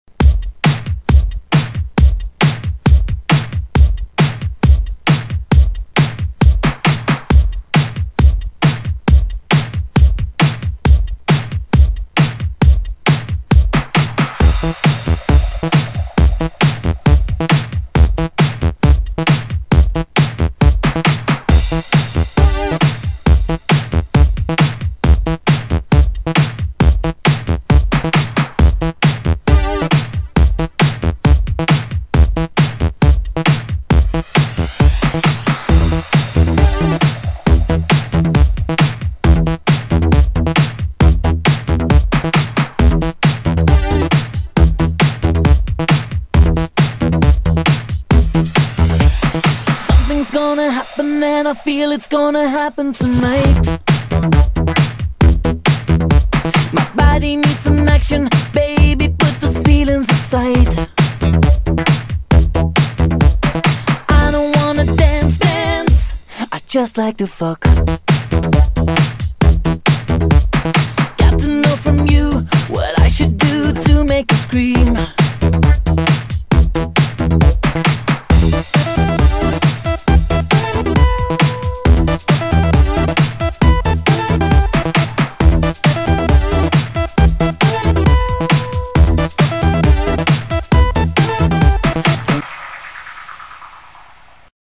BPM135--1
Audio QualityPerfect (High Quality)